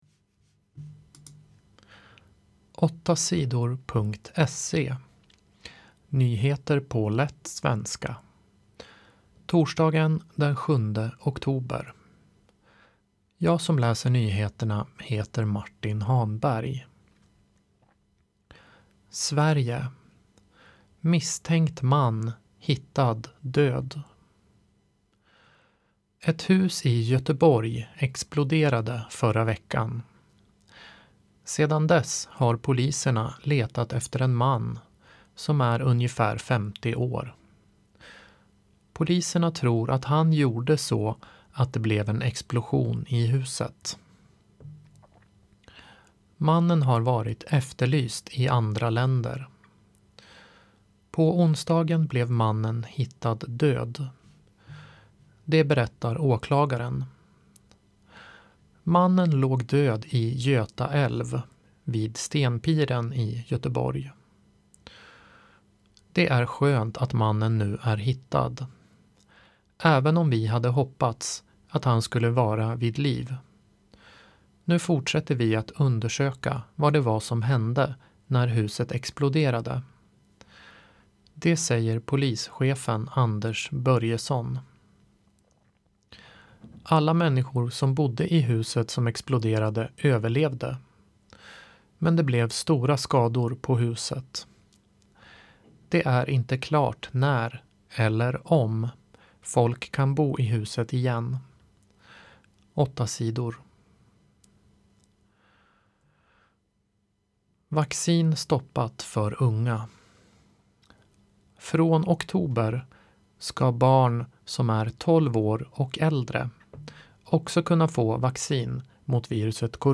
Nyheter på lätt svenska den 7 oktober